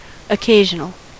Ääntäminen
US : IPA : [əˈkeɪʒənl]